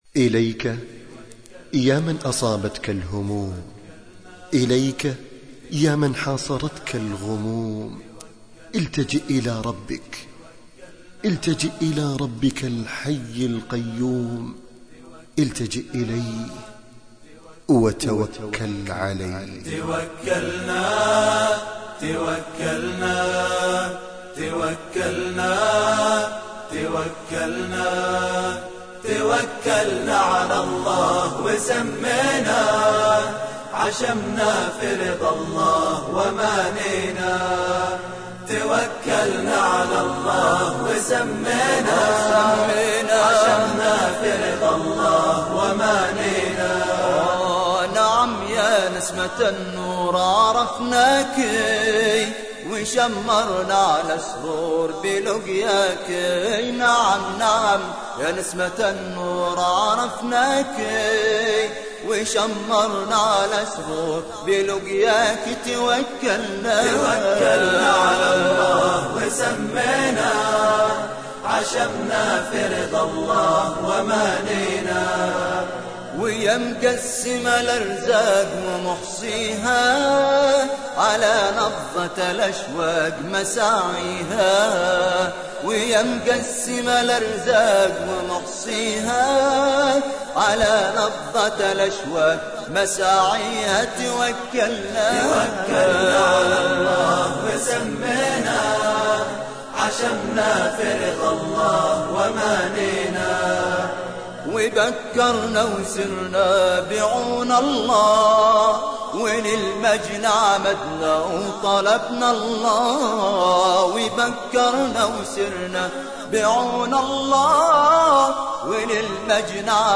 أنشاد :